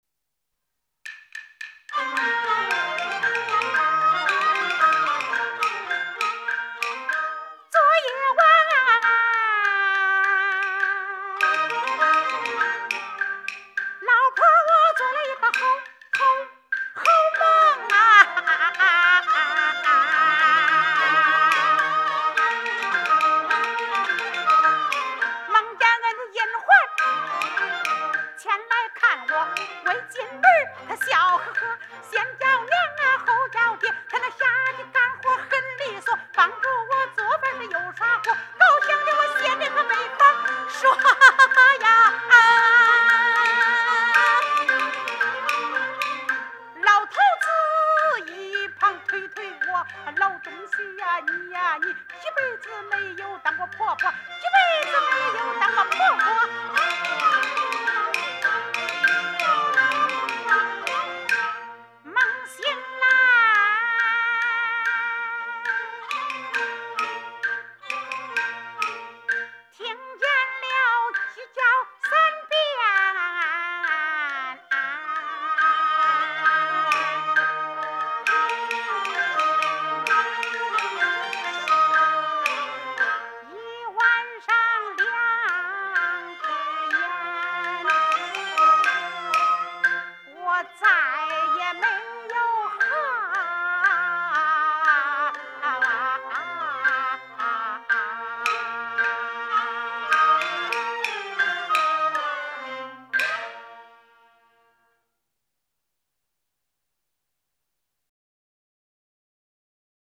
现代豫剧